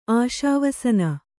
♪ āśāvasana